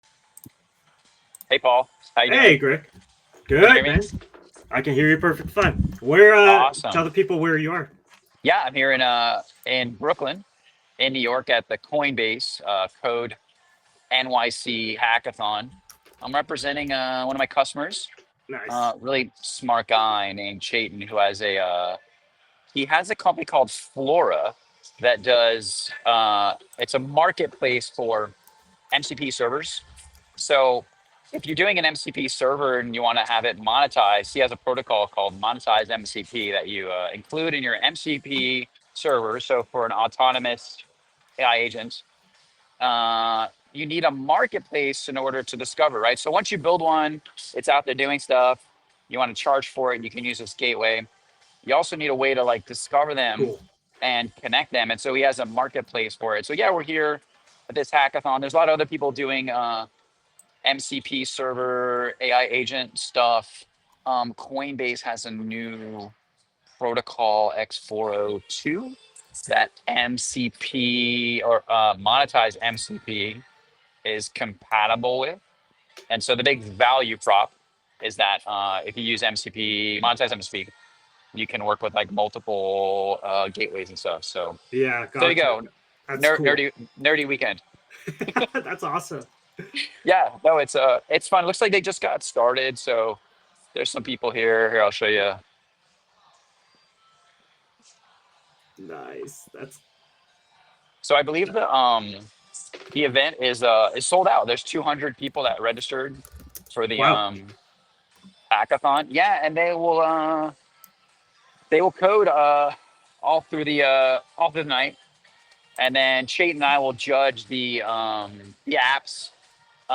Live From Coinbase CODE:NYC Hackathon